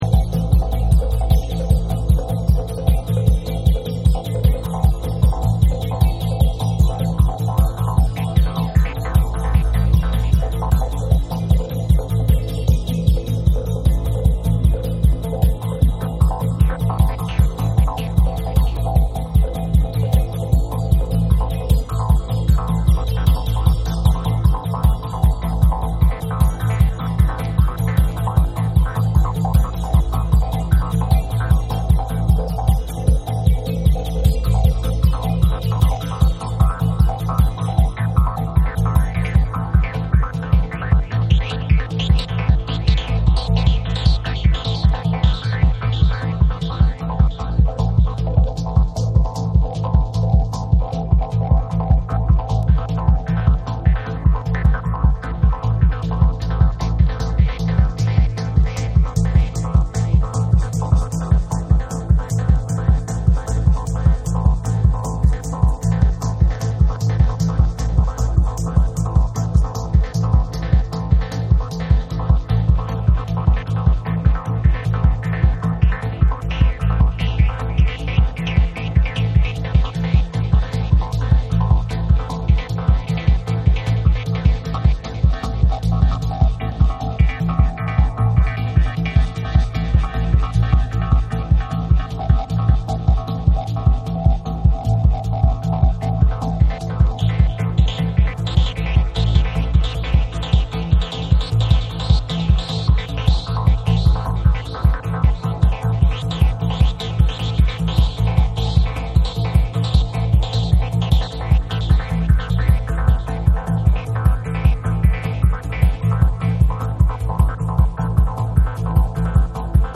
全体をスモーキーな空気感が支配するスペイシーなミニマル・テクノ
TECHNO & HOUSE